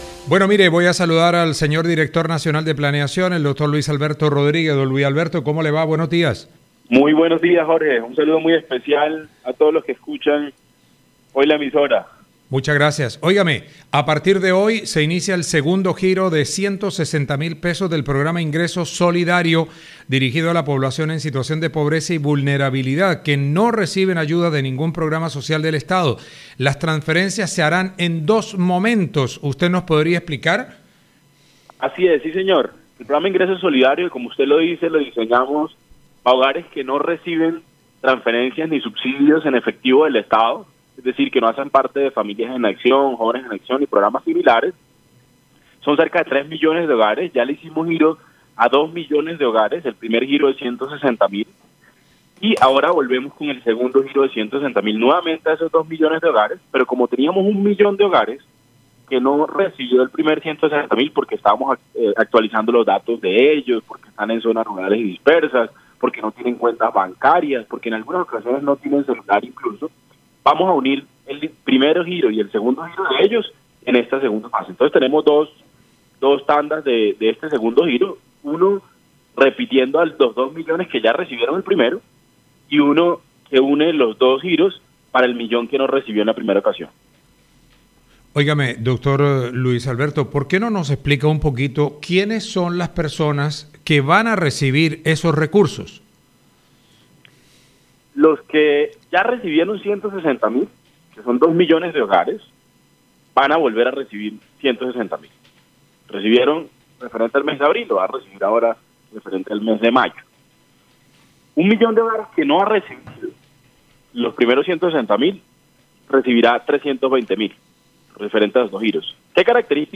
Director de Planeación Nacional explica cómo funcionará el segundo pago del Ingreso Solidario